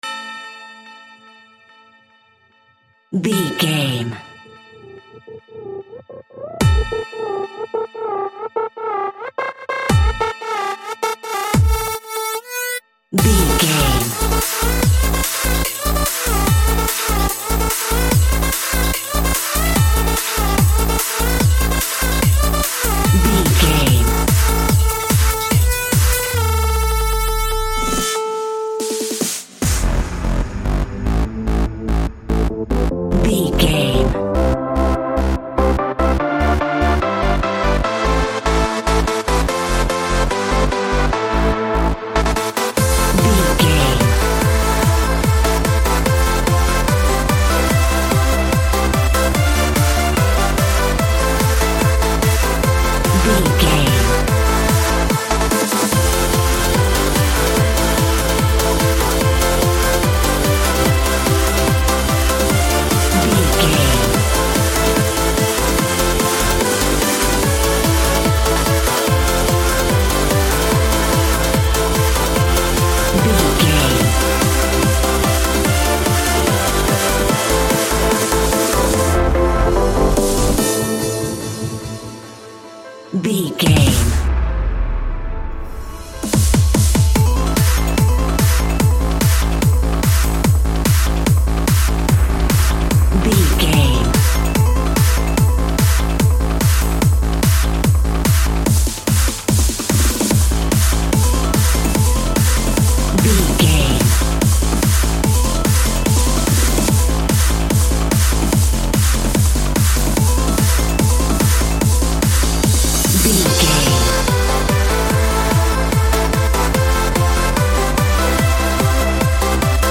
In-crescendo
Aeolian/Minor
Fast
energetic
uplifting
hypnotic
frantic
drum machine
synthesiser
percussion
piano
acid house
electronic
uptempo
synth leads
synth bass